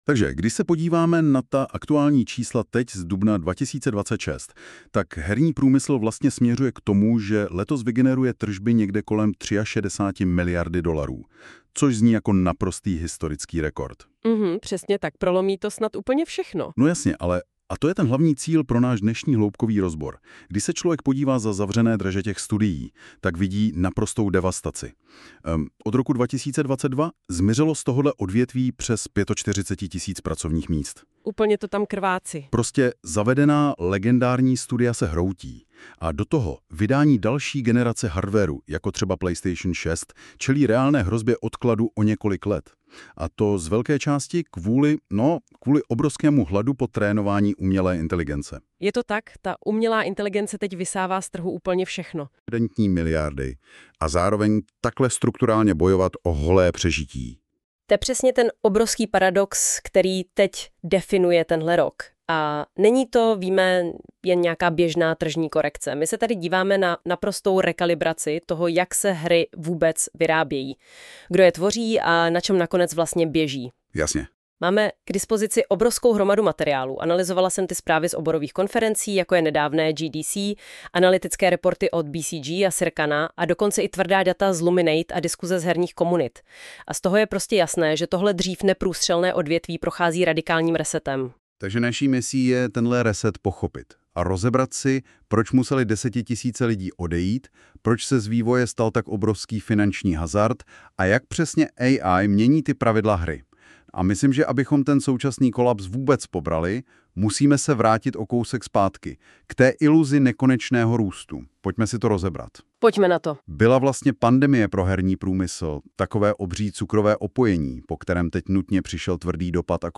A heated one.